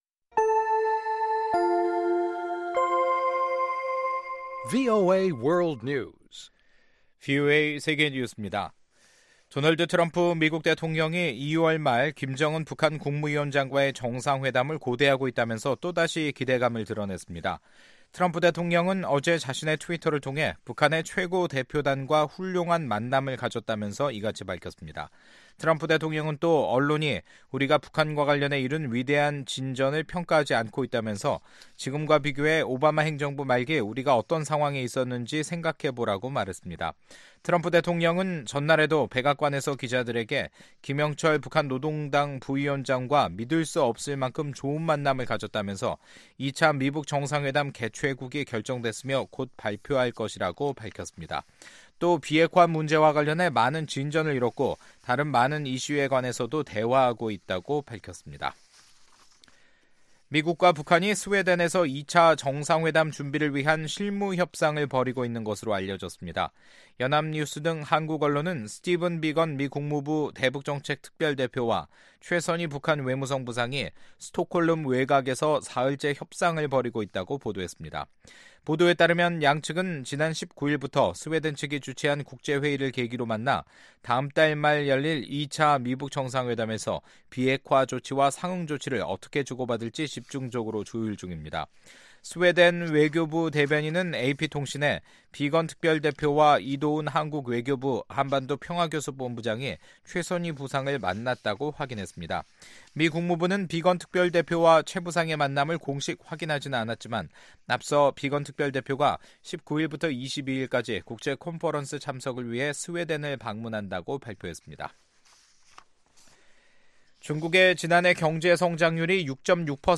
VOA 한국어 간판 뉴스 프로그램 '뉴스 투데이', 2019년 1월 21일 2부 방송입니다. 2차 미-북 정상회담 개최국이 결정됐으며, 비핵화 문제와 관련해 많은 진전을 이뤘다고 도널드 트럼프 대통령이 밝혔습니다.